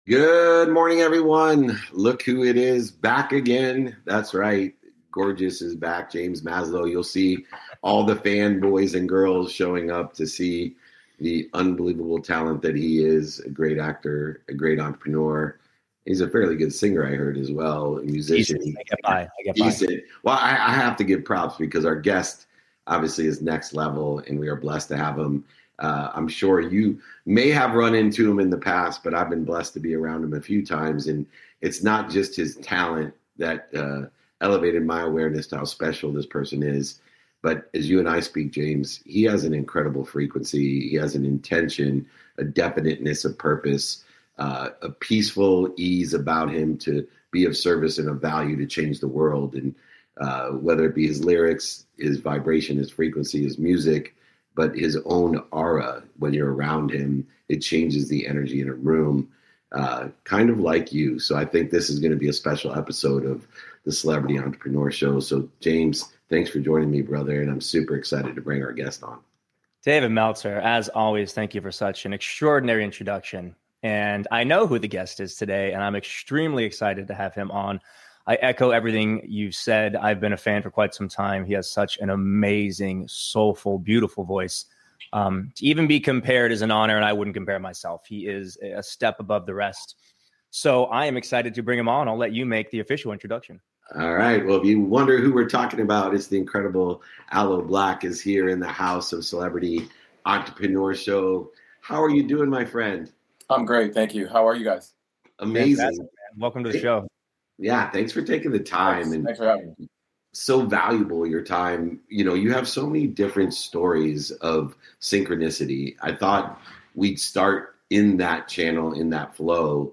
In today’s episode, James Maslow and I welcome Aloe Blacc for a conversation about intention, community, and the real work behind creative success.